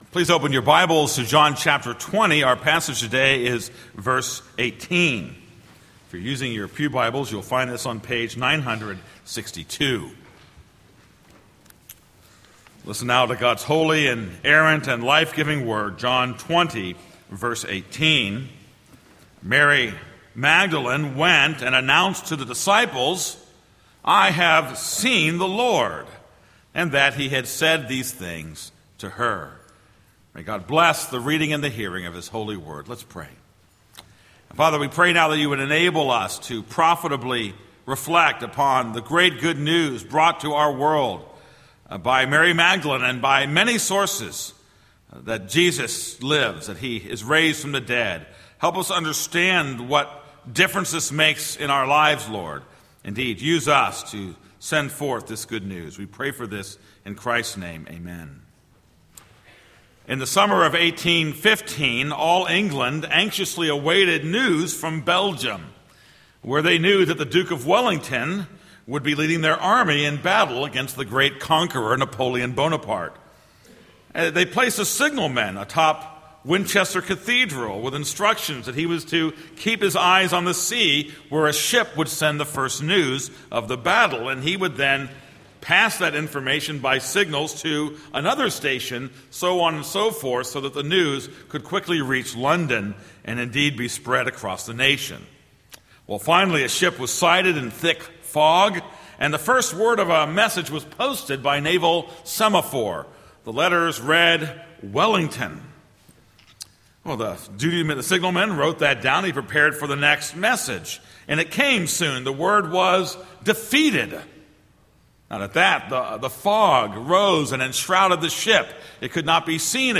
This is a sermon on John 20:18.